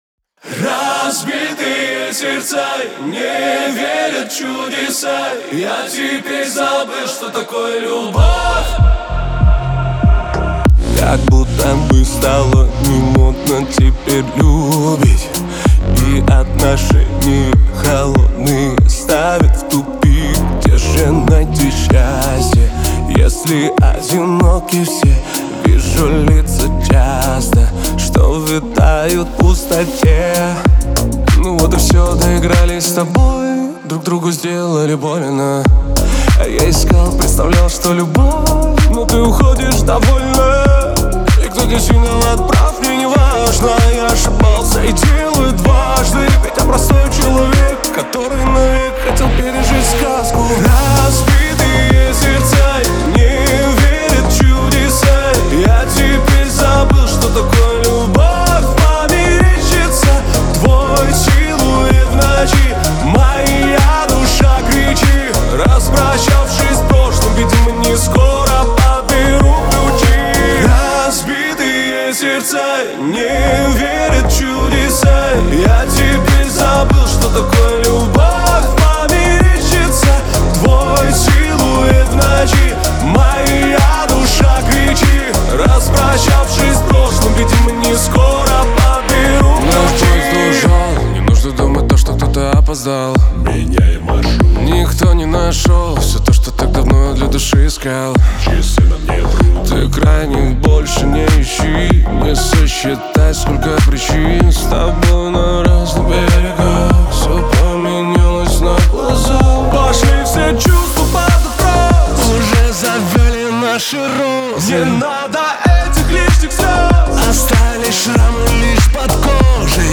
грусть
диско
Шансон